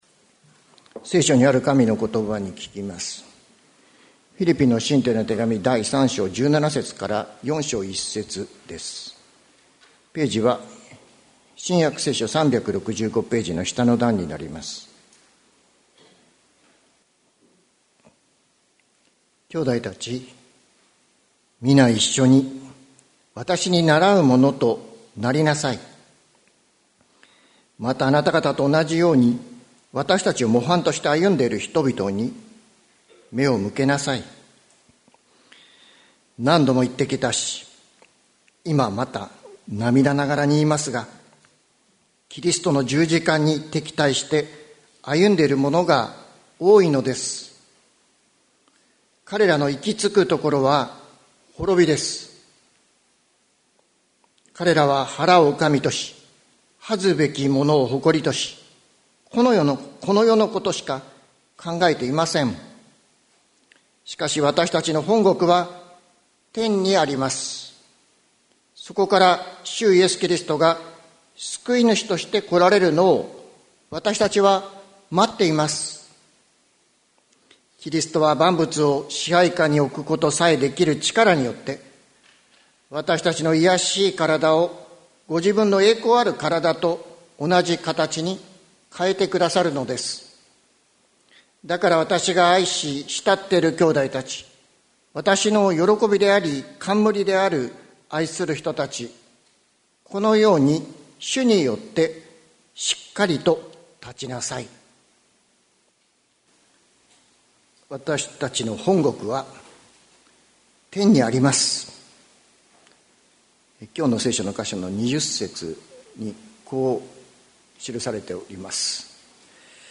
2024年06月30日朝の礼拝「あなたの居場所」関キリスト教会
説教アーカイブ。